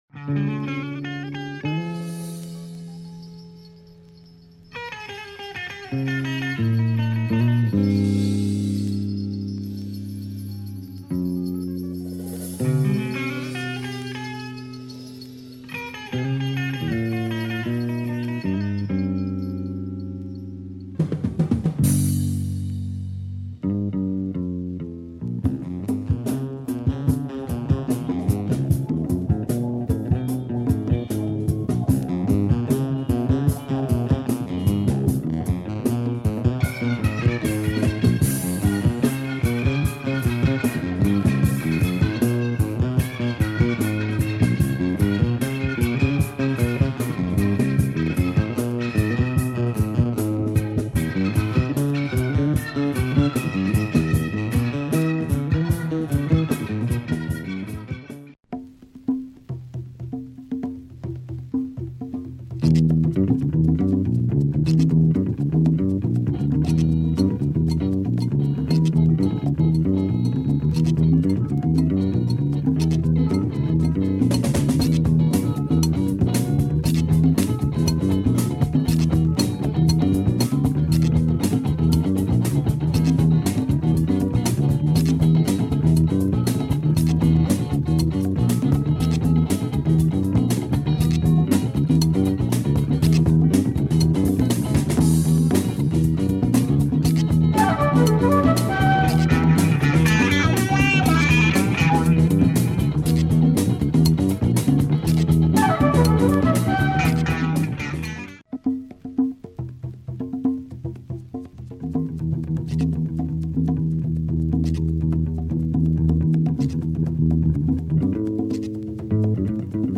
Groovy jazz with electric piano and breaks…
It is just European big band funk at its best.